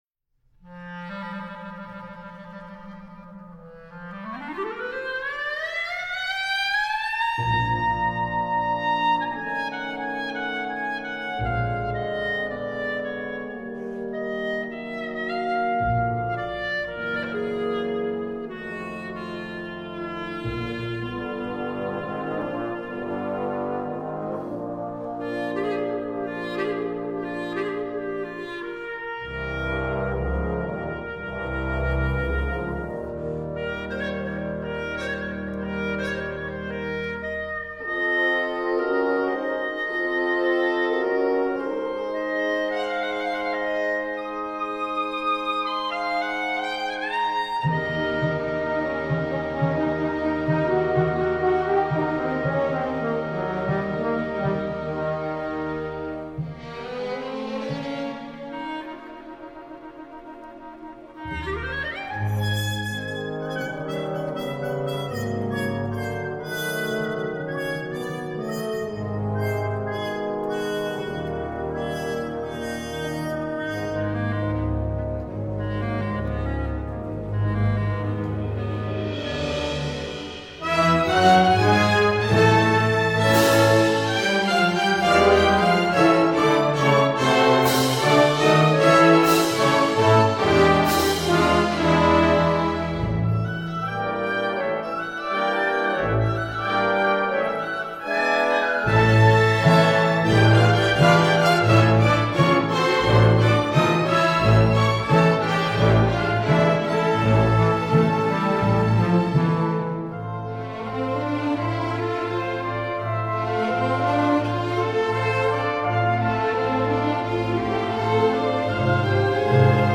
Buchanan Symphony Orchestra To begin playback, click or touch the line above the track you want to listen to.